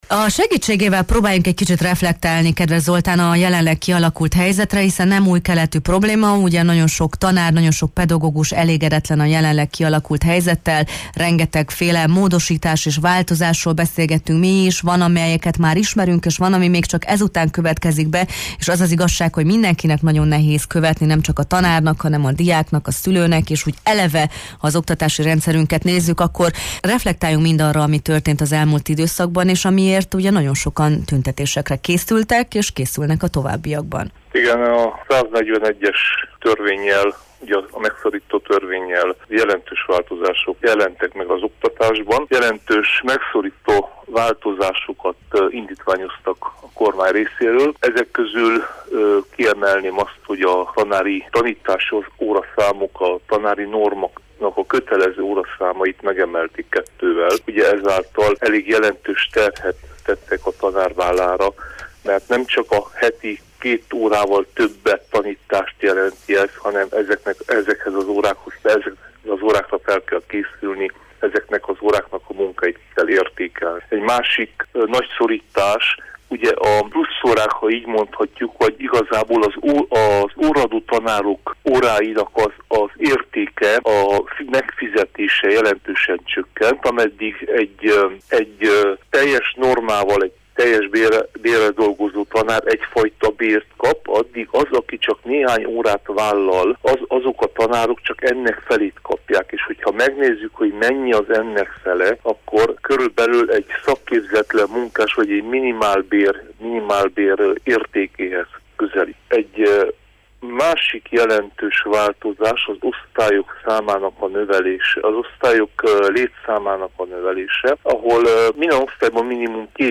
akivel a Jó reggelt, Erdély!-ben beszélgettünk: